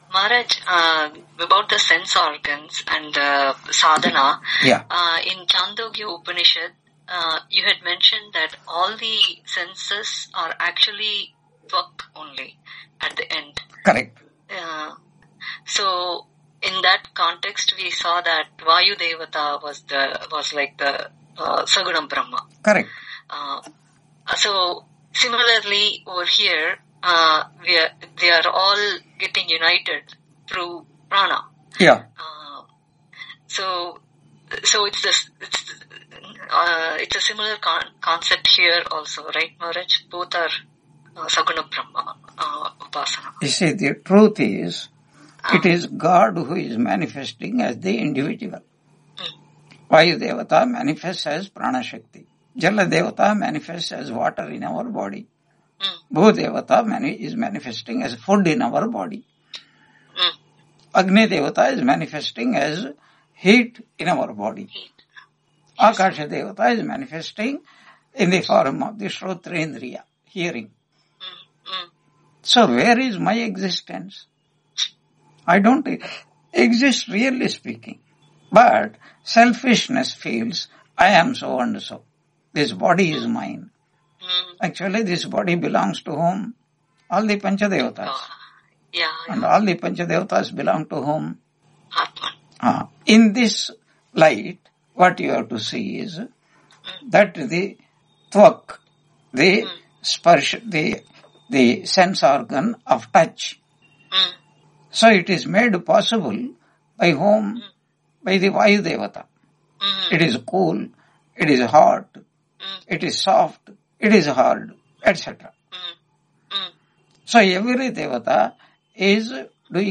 Brihadaranyaka Upanishad Ch.1.3 Lecture 17 on 21 March 2026 Q&A - Wiki Vedanta